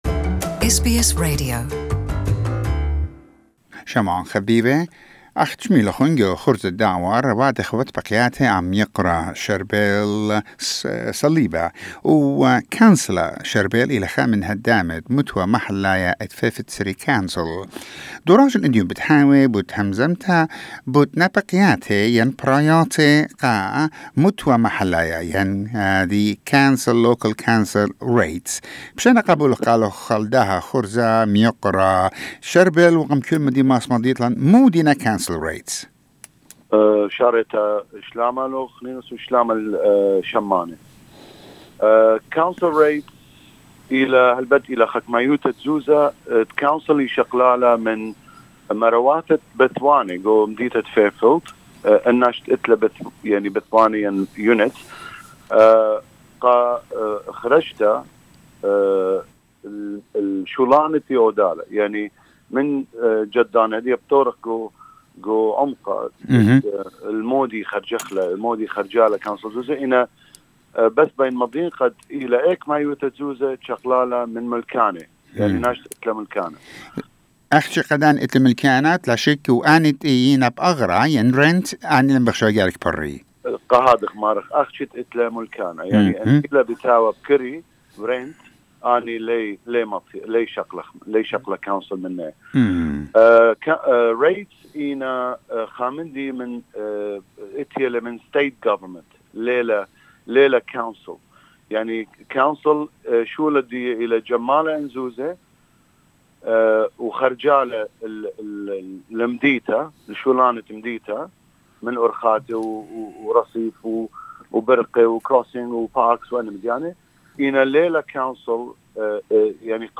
Councillor Sharbel Saliba from Fairfield City Council talks about the council's rates, what are they? how much? who should pay it and how often? what happens if you are late in paying the quarterly bill? who puts a value on your land? Many questions that will be answered in this interview